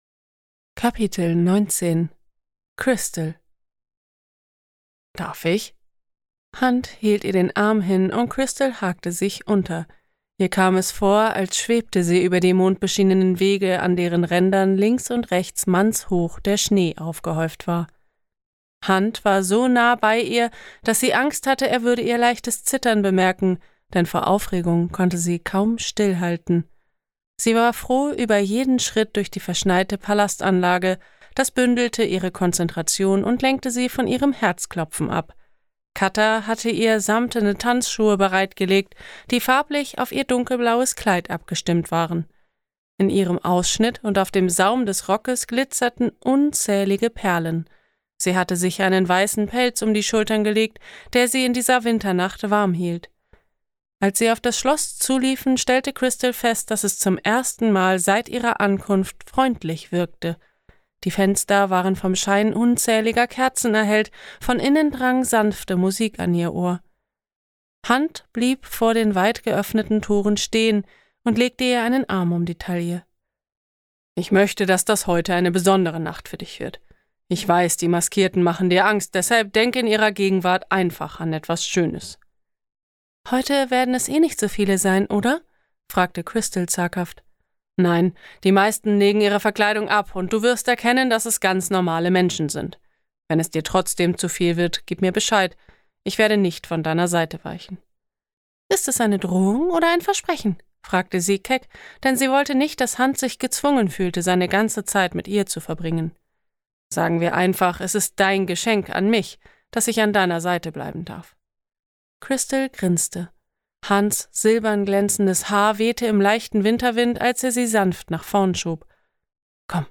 Hörprobe Kingdom of Silk